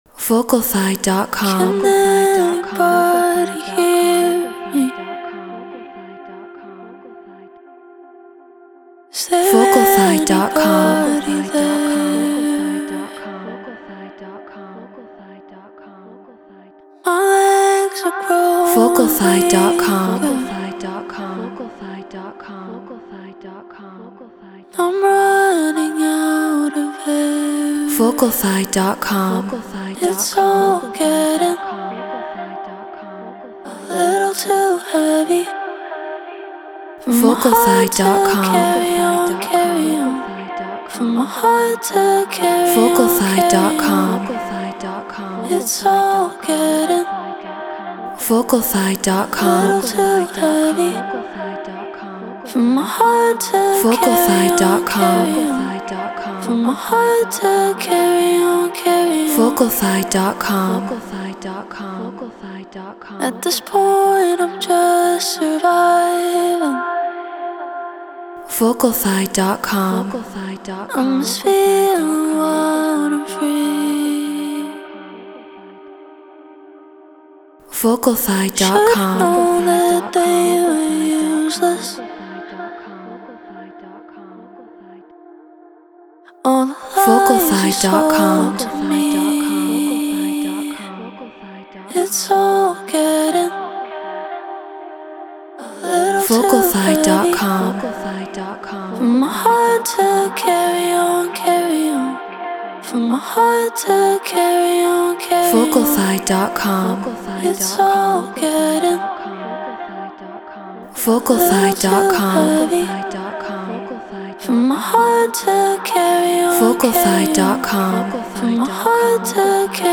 House 124 BPM Cmin
Shure SM7B Apollo Solo Logic Pro Treated Room